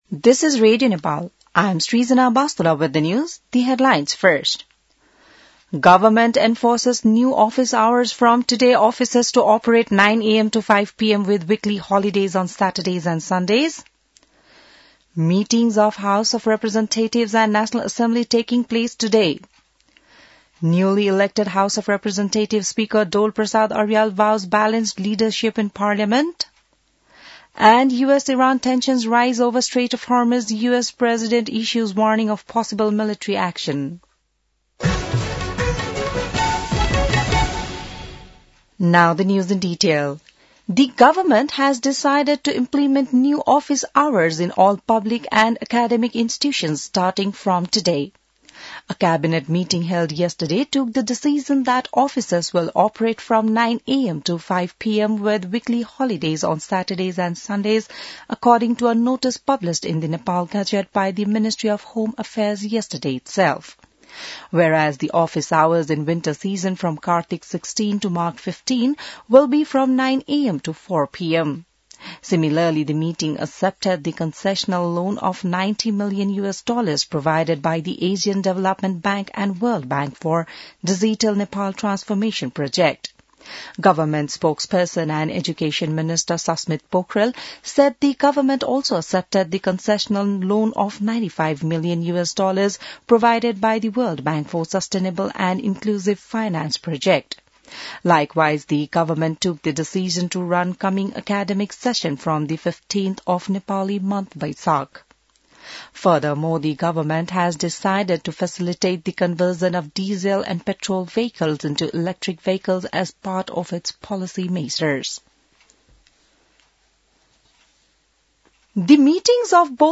बिहान ८ बजेको अङ्ग्रेजी समाचार : २३ चैत , २०८२